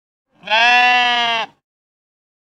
sheep-bleet.ogg